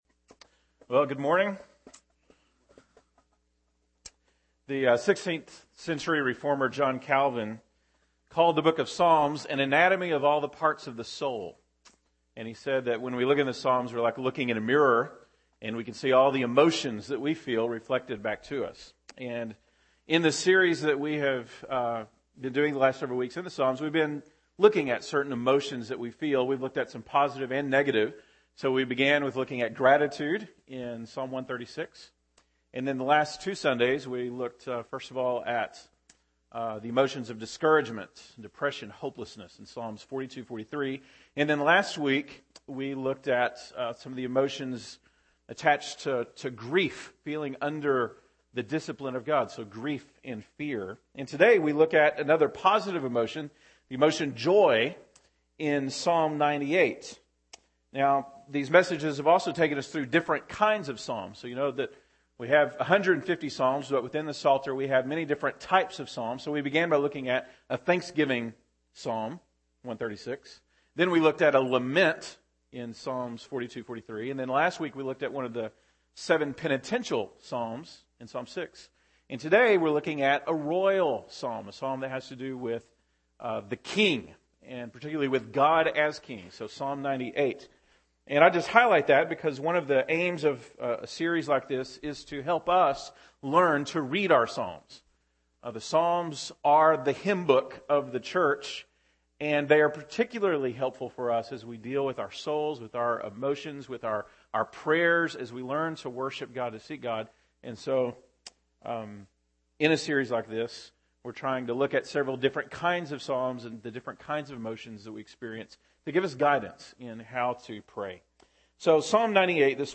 December 16, 2012 (Sunday Morning)